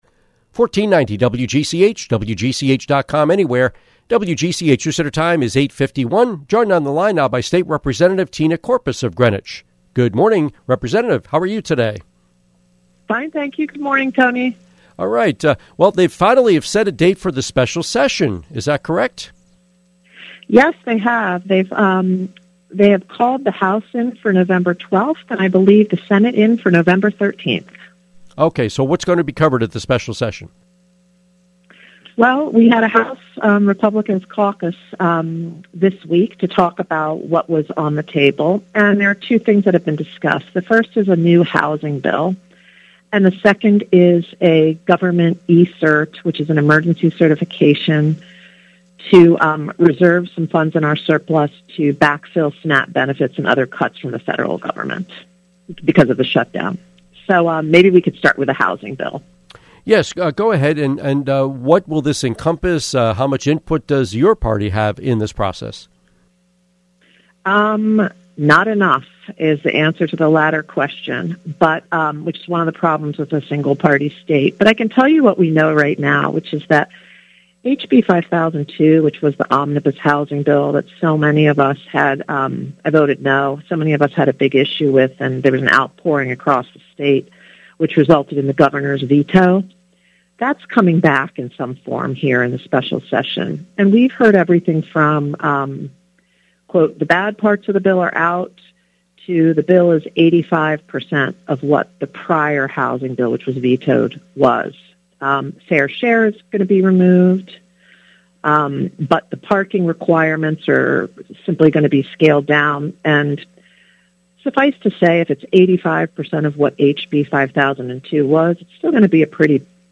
Interview with State Representative Tina Courpas